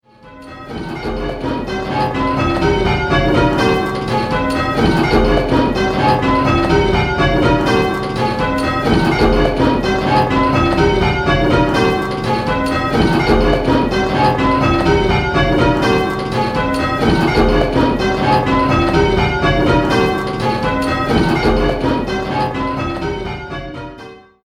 In this game you will be answering similar questions, but with 10 bells to choose from, the choices are much larger and there are more different notes to identify!